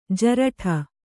♪ jaraṭha